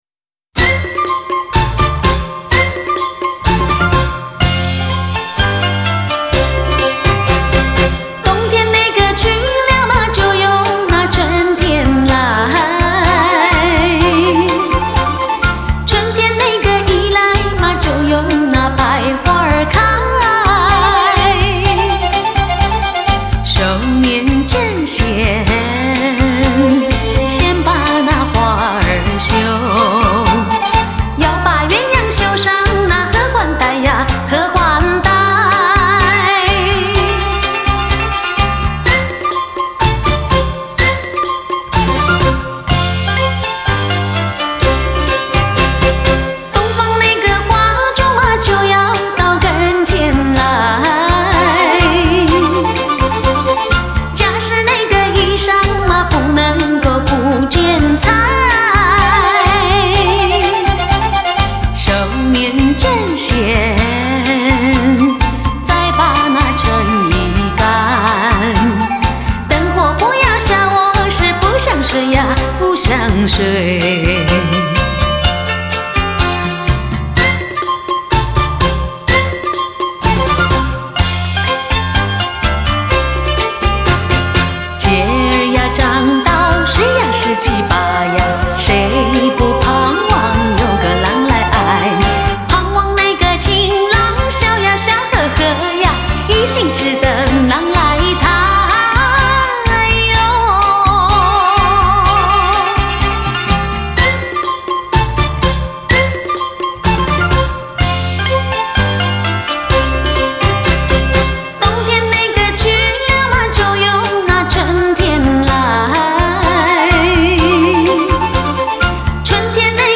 专辑类别：录音室专辑
人聲柔美親切，樂隊演繹可圈可點，編曲新穎錄音上乘，雖是老歌但可聽性極強，是不可多得的好碟。